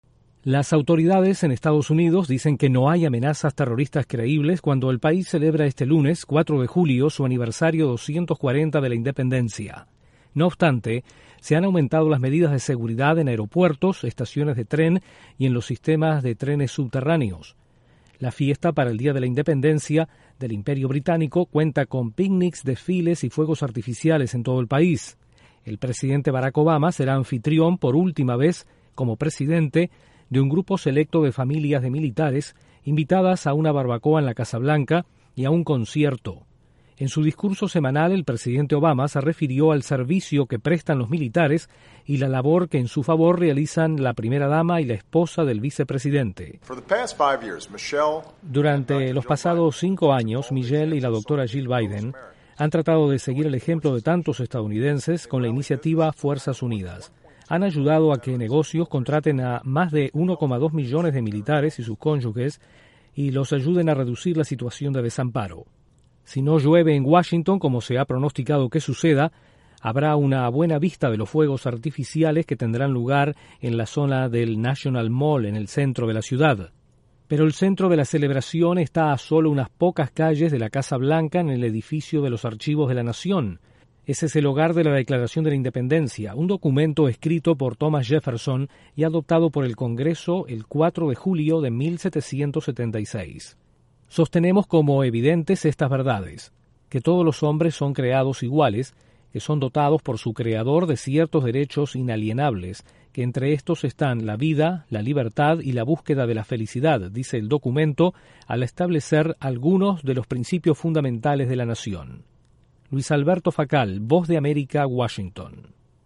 EE.UU. celebra con diferentes actos y elevadas medidas de seguridad este lunes el aniversario 240 de su independencia. Desde la Voz de América en Washington informa